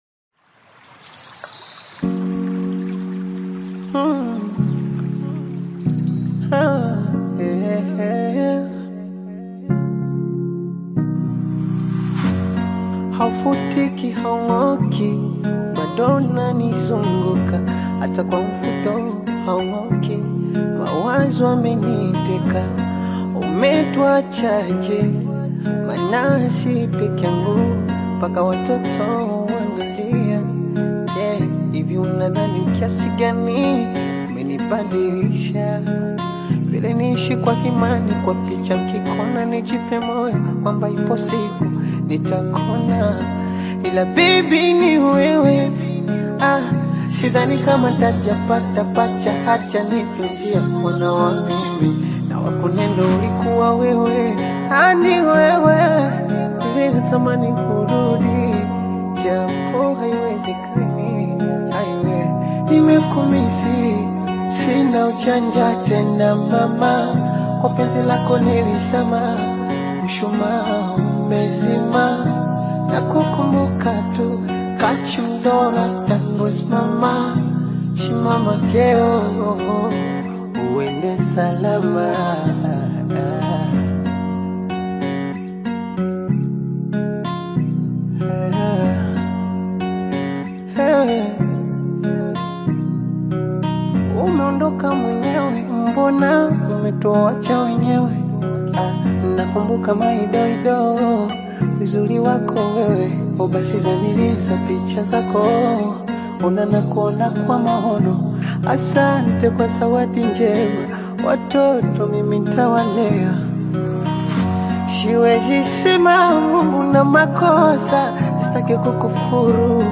heartfelt Afro-Pop/Bongo Flava single
delivers expressive vocals and melodic hooks
With its warm rhythms and relatable themes